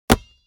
دانلود آهنگ تصادف 51 از افکت صوتی حمل و نقل
جلوه های صوتی
دانلود صدای تصادف 51 از ساعد نیوز با لینک مستقیم و کیفیت بالا